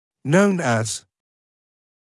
[nəun æz][ноун эз] известный как